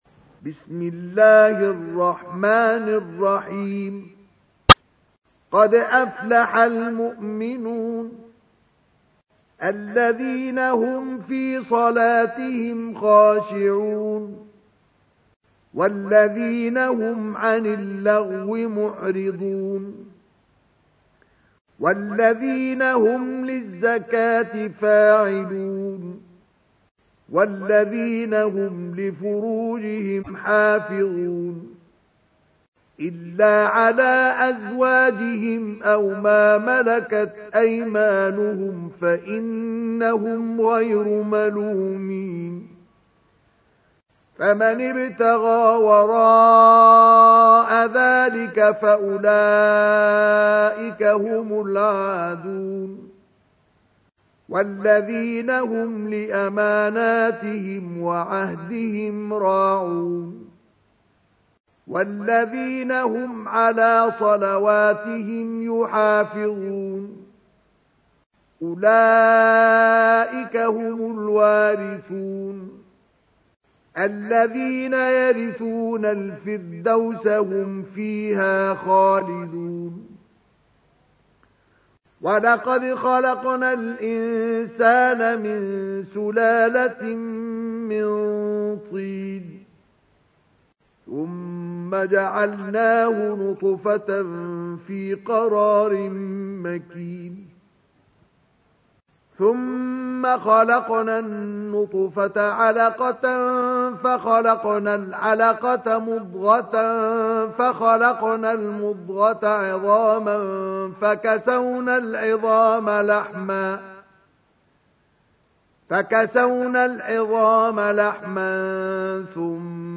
تحميل : 23. سورة المؤمنون / القارئ مصطفى اسماعيل / القرآن الكريم / موقع يا حسين